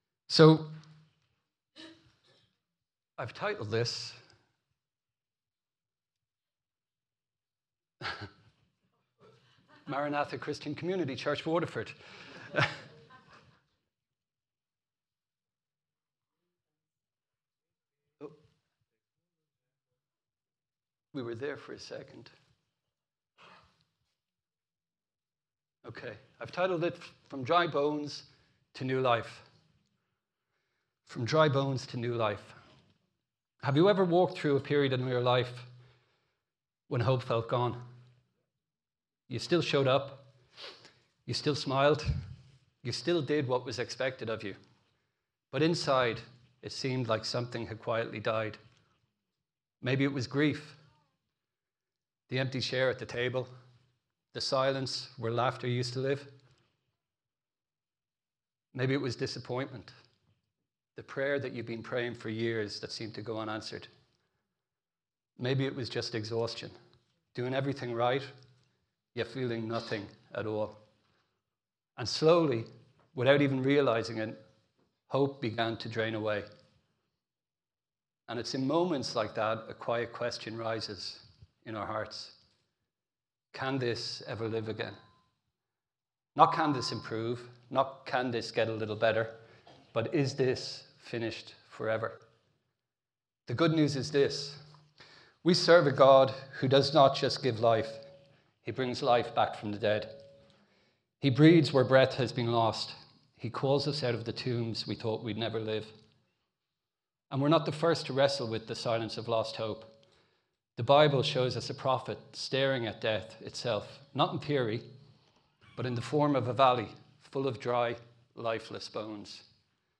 A message from the series "Messages 2026."